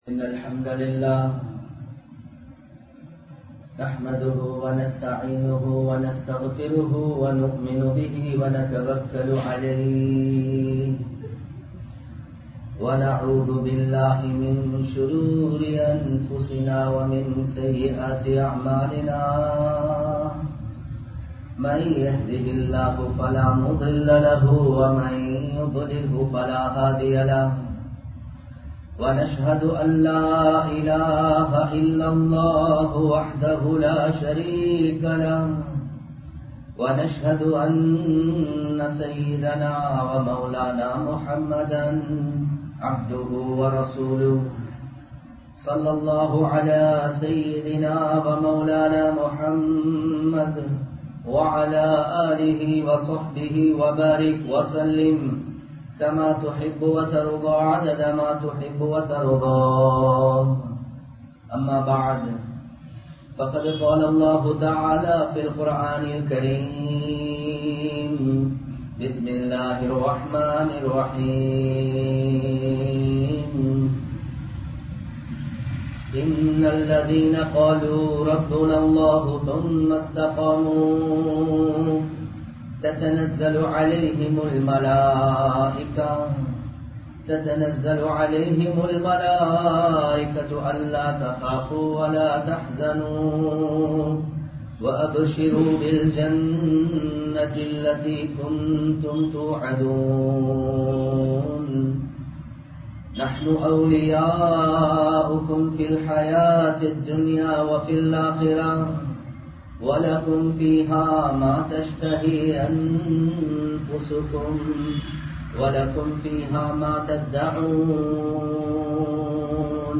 Suvarkaththu Vaalifarhal (சுவர்க்கத்து வாலிபர்கள்) | Audio Bayans | All Ceylon Muslim Youth Community | Addalaichenai